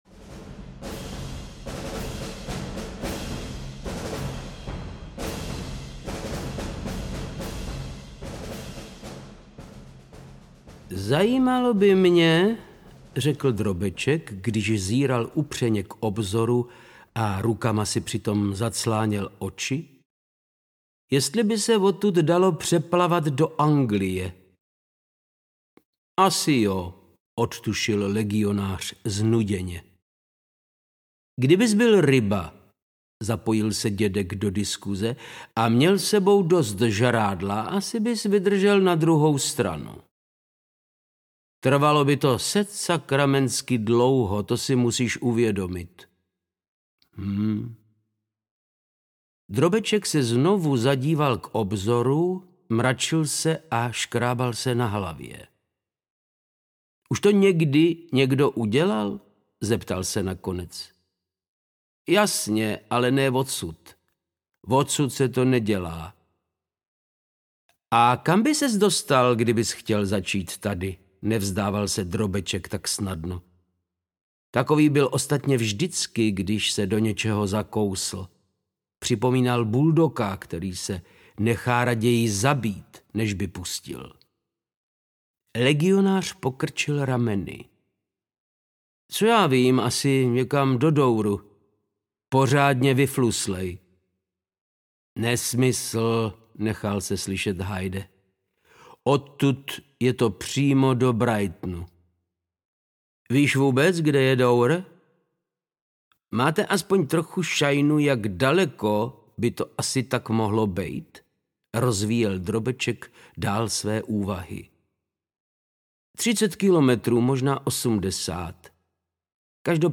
Ukázka z knihy
• InterpretVáclav Knop